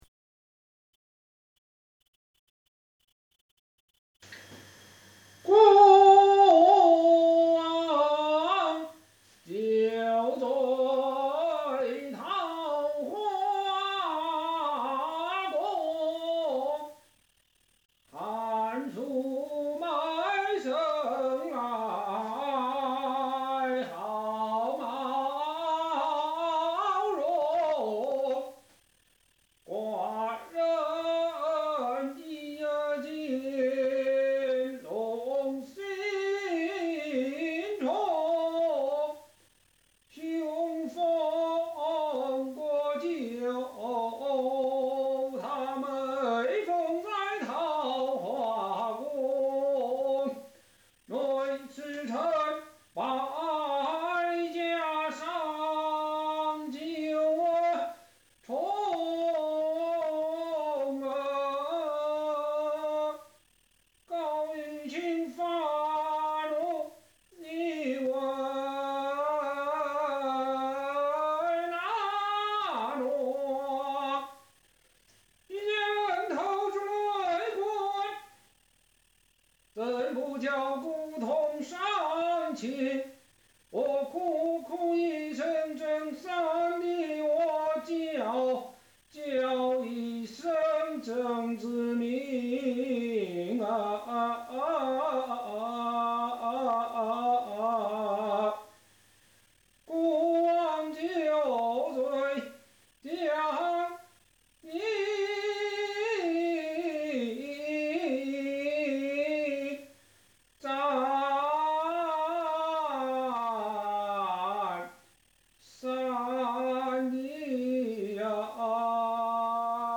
京剧<<斩黄袍
高派的老生戏,经俺一唱,全是顺耳的罗派.